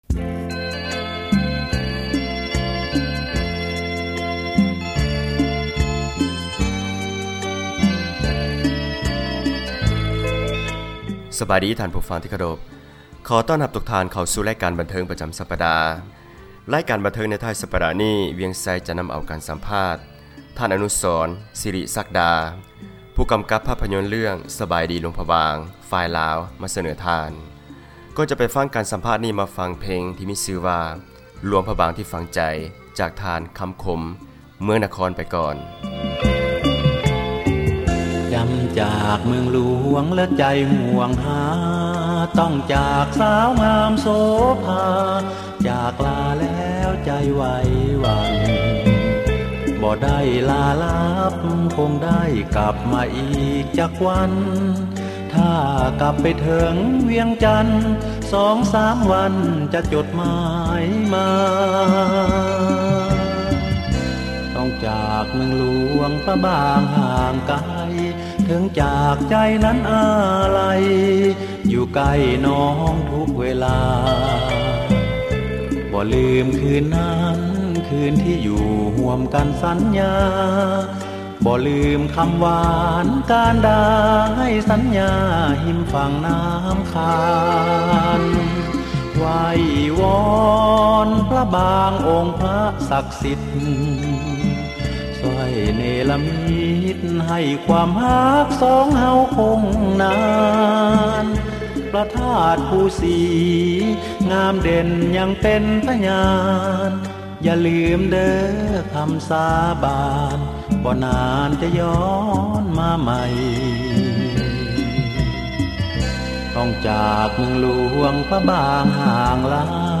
ການສັມພາດ